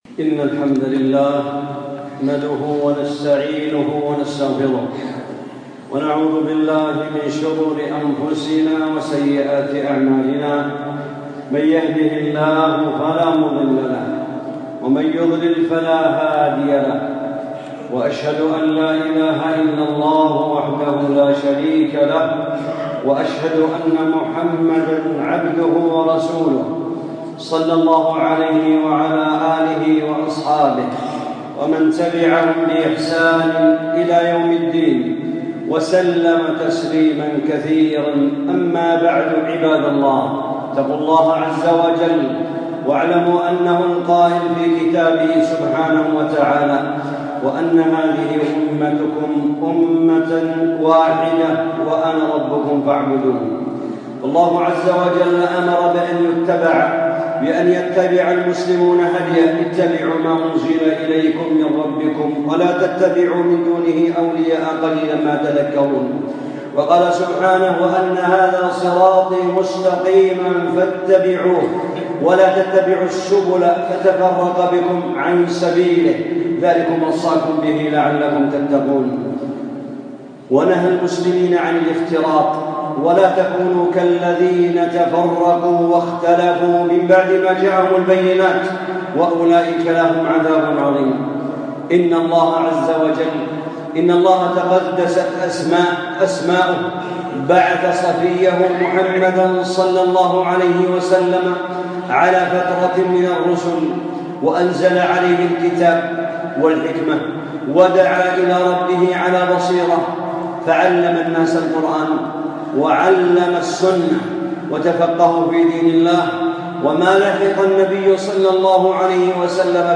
خطبة - مشابهة الخوارج المعاصرين للخواج الأولين في الأصول